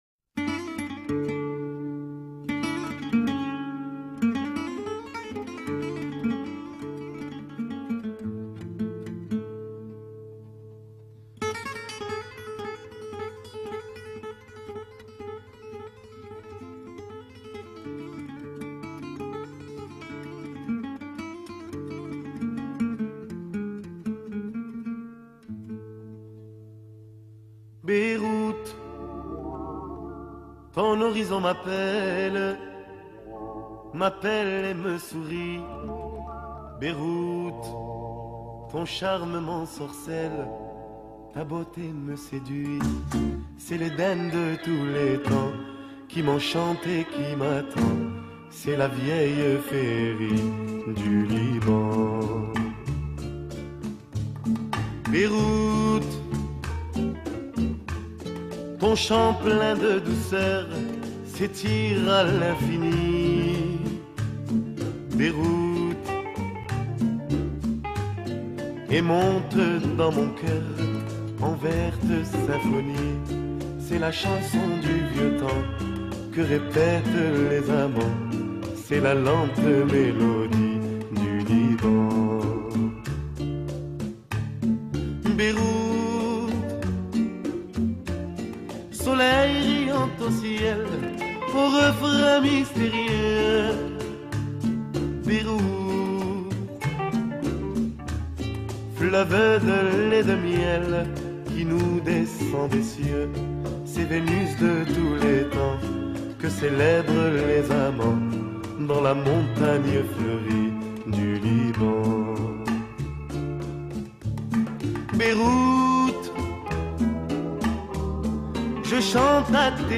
Mélangeant une poésie envoûtante et une musique dépouillée